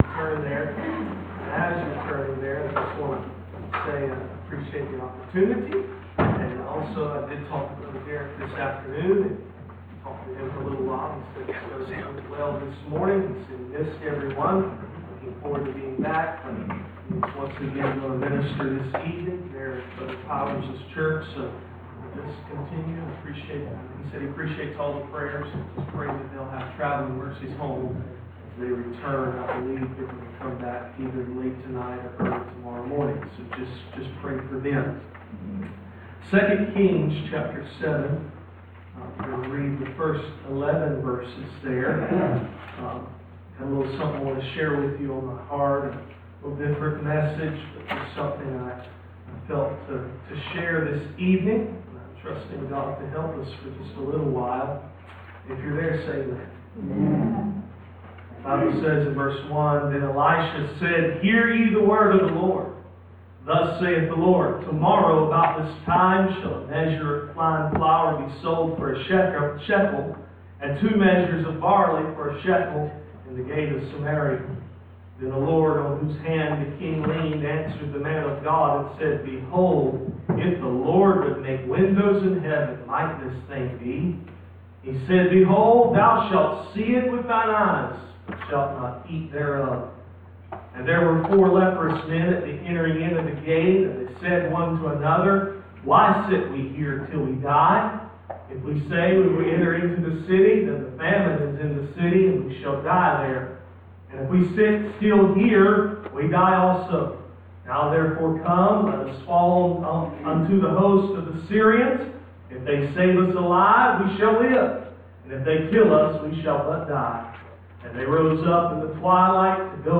Passage: 2 Kings 7:3-11 Service Type: Sunday Evening